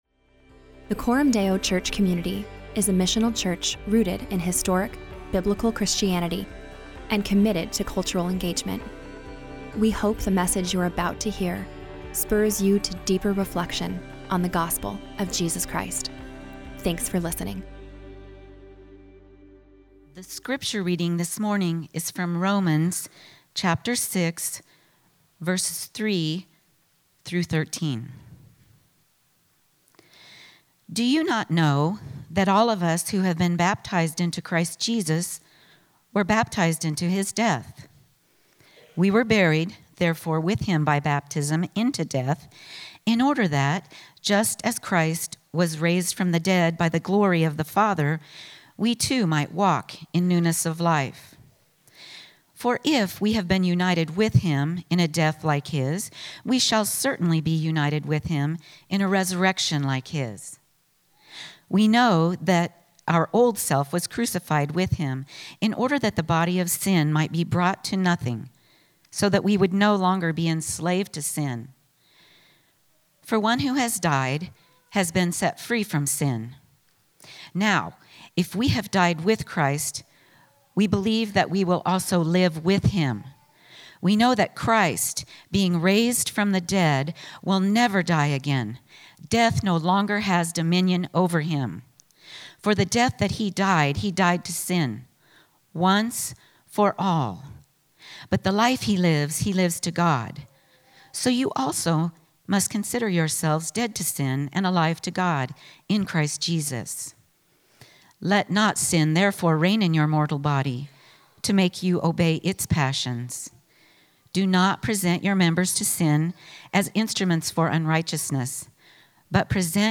God doesn't just want us to celebrate the resurrection as an event; he wants us to live a resurrection life. In this sermon, we explore the doctrine of union with Christ and what it means to 'consider ourselves dead to sin and alive to God in Christ Jesus.'